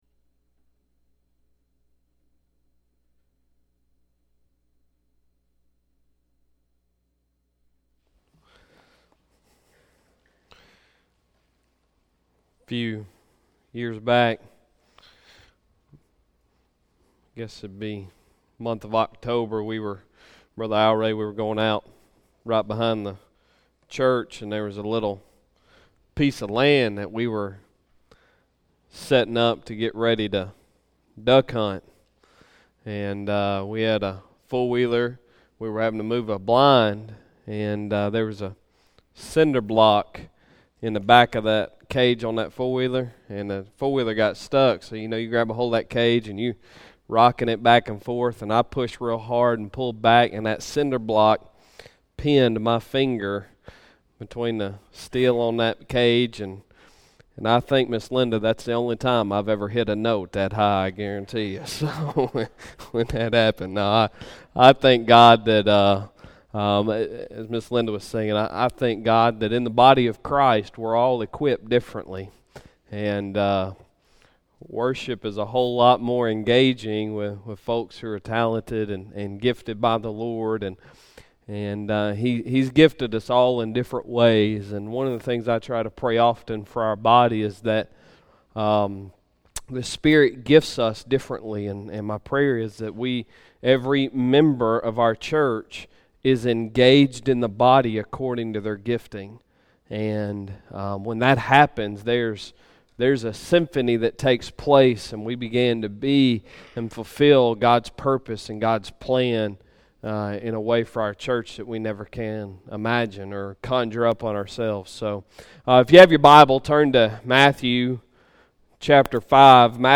Sunday Sermon September 16, 2018
Morning Worship